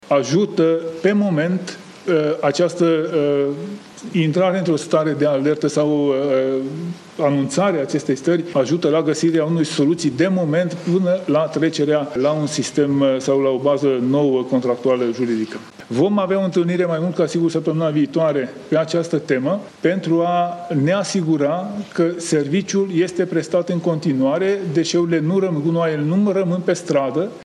O reacție în acest caz a venit și de la ministrul Mediului, Tánczos Barna, în scandalul gunoiului din Sectorul 1.
04iun-13-Tanczos-Barna-despre-sectorul-1.mp3